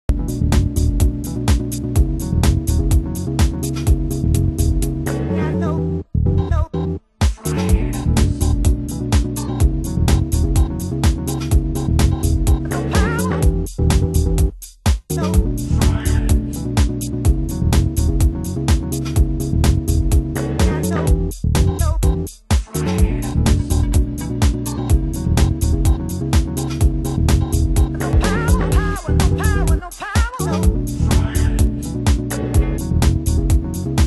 コード進行やサンプル使いが、ライトな印象で洗練されたDEEPトラック！